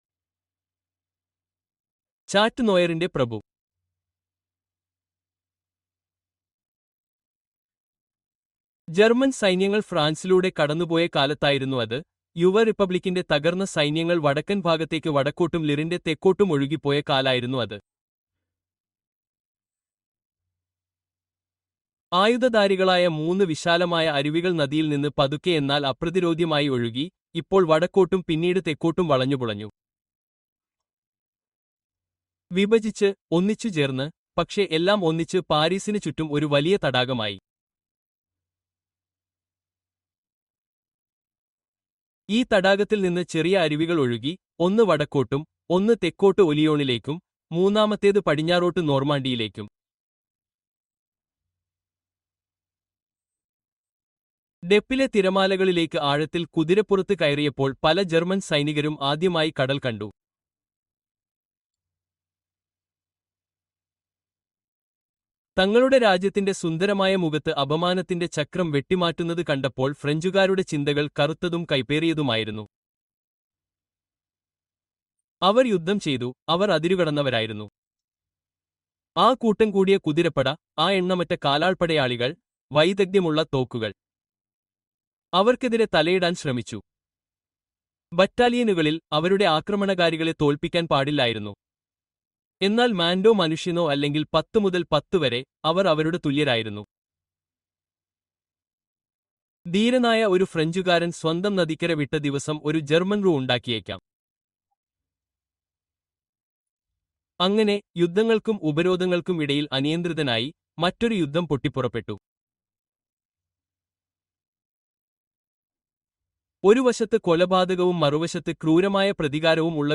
Baskerville Hall Revealed: Secrets of the Legendary Estate (Audiobook)